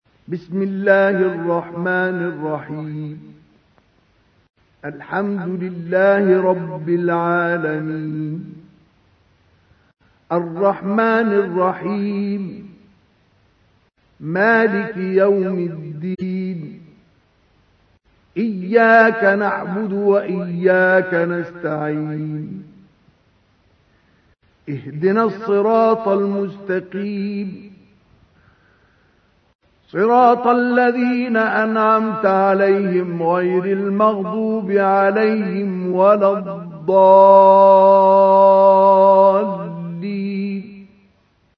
تحميل : 1. سورة الفاتحة / القارئ مصطفى اسماعيل / القرآن الكريم / موقع يا حسين